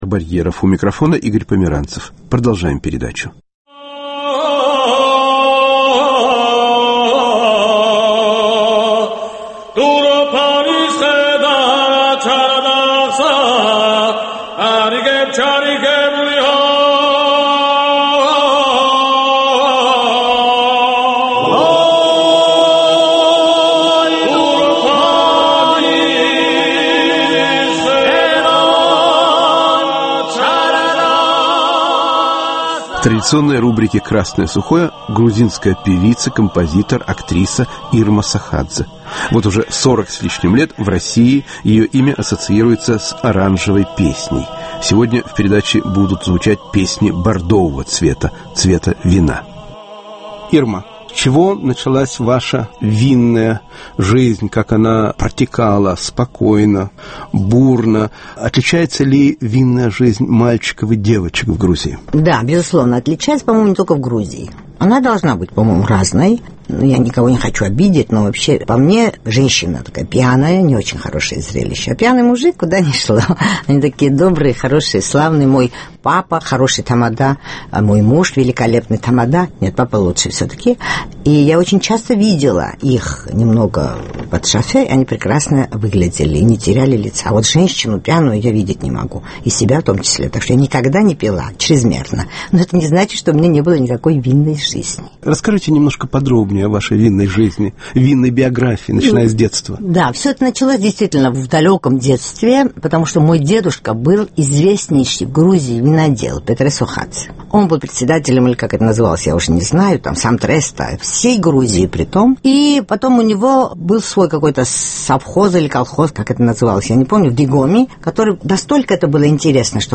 Разговор о вине и музыке с грузинской певицей, композитором, актрисой Ирмой Сохадзе